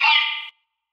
VOX - TAKE CARE.wav